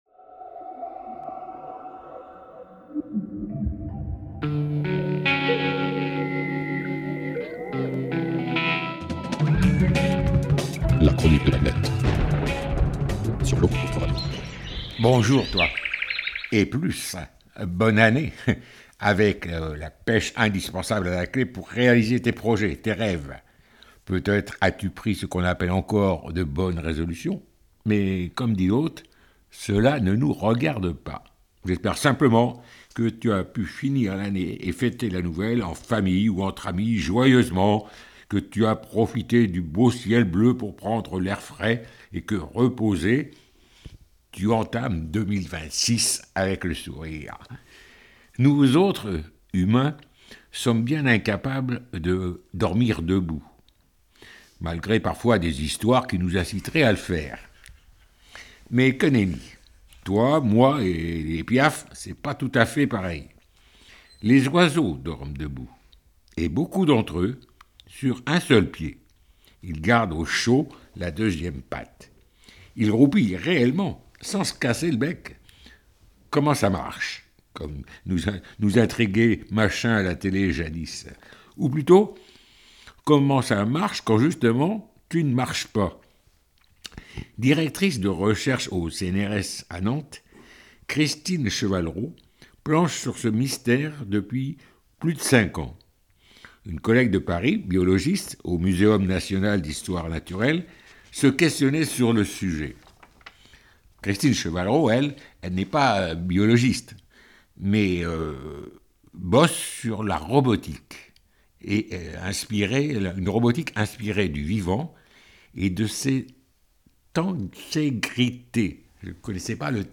Quelques petites minutes pour un billet d'humeur style " j'aime, je partage " et autres pirouettes contre la bêtise humaine quand elle colle à nos semelles.